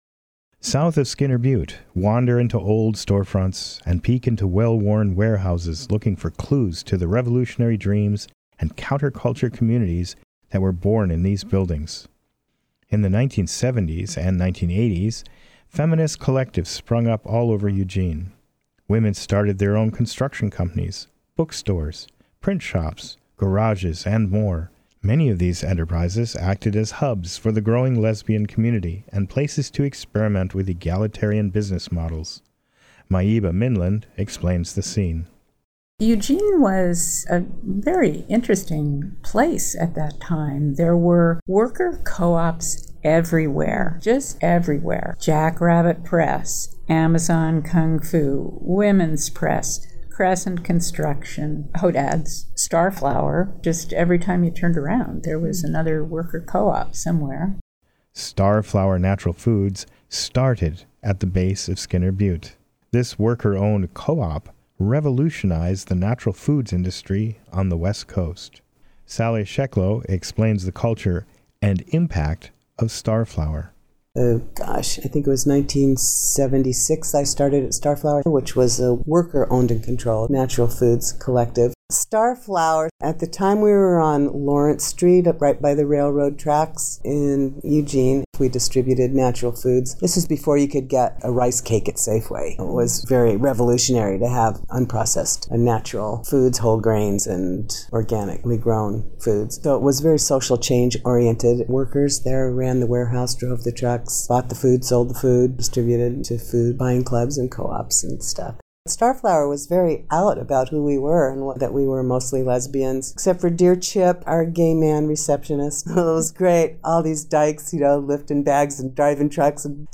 Listen to the members of the lesbian community discuss life in Eugene.